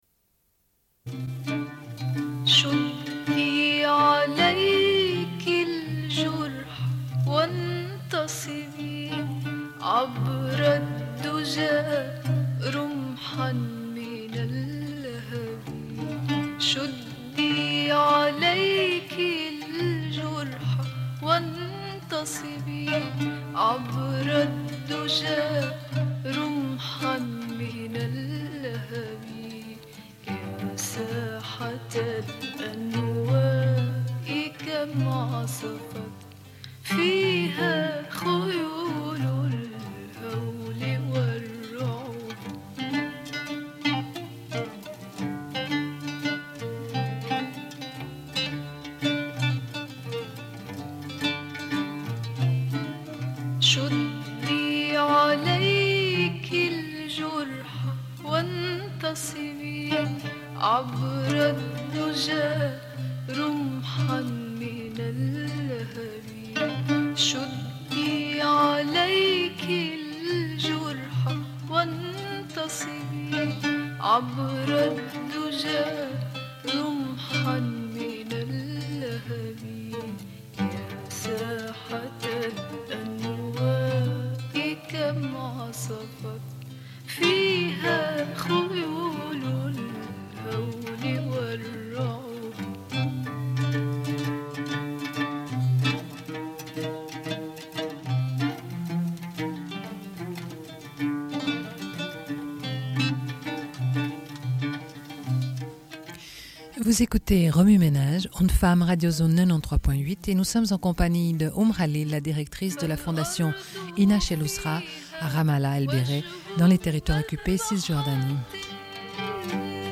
Une cassette audio, face B28:47